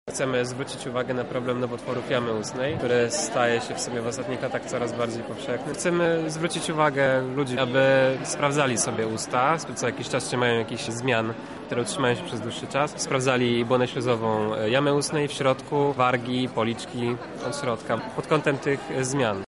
Studenci stomatologii